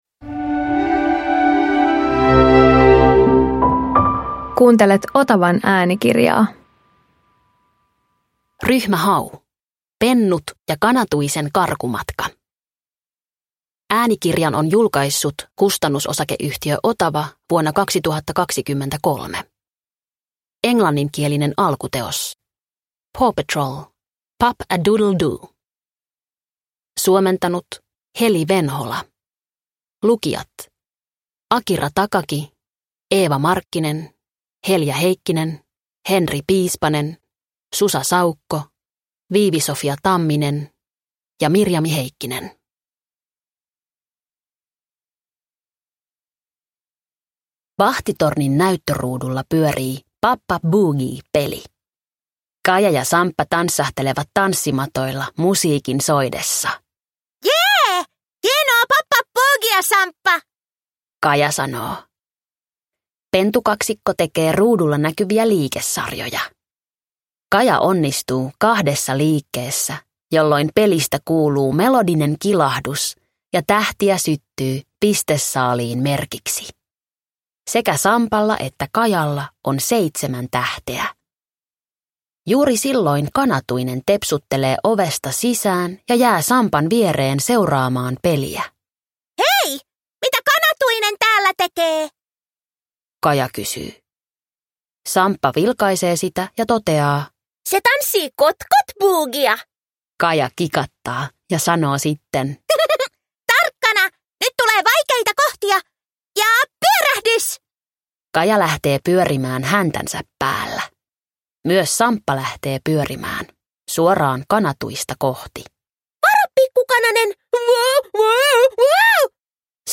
Ryhmä Hau Pennut ja Kanatuisen karkumatka – Ljudbok – Laddas ner